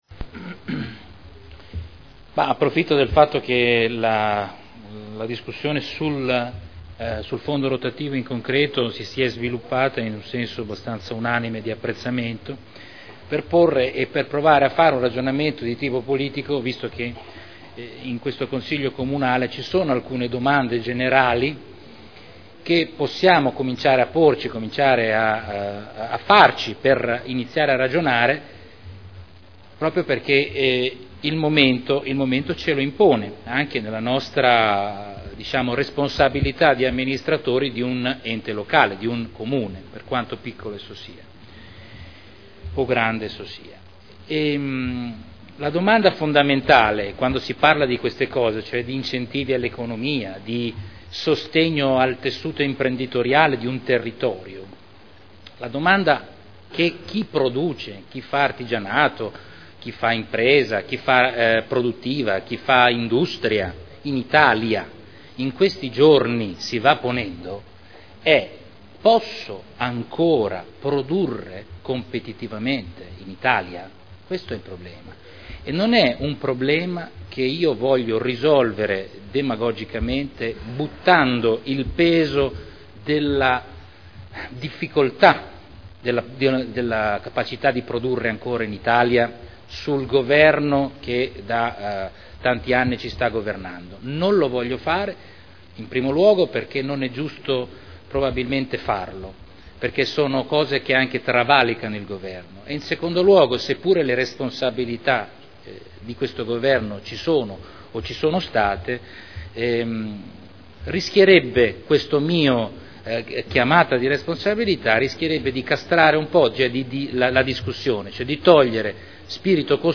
Salvatore Cotrino — Sito Audio Consiglio Comunale
Dibattito su proposta di deliberazione. Fondo provinciale per il sostegno all’innovazione delle imprese – Approvazione dello schema di convenzione per il rinnovo del fondo rotativo